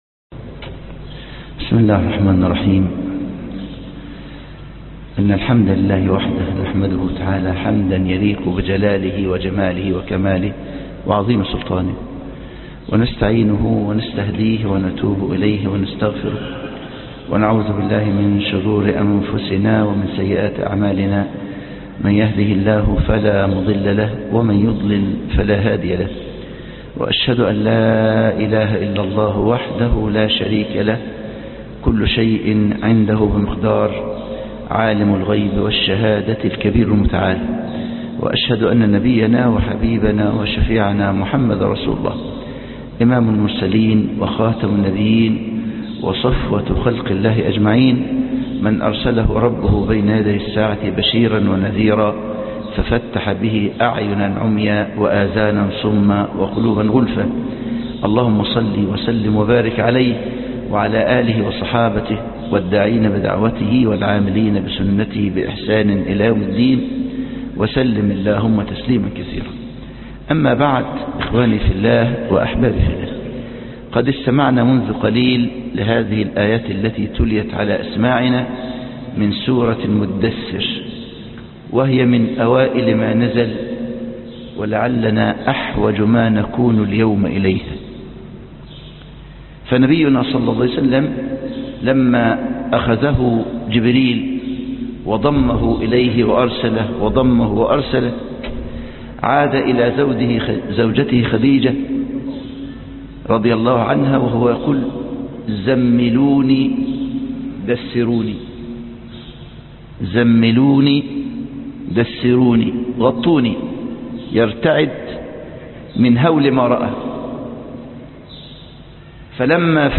تفسير موجز